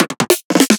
VR_drum_fill_tunedsnares_150_Fmin.wav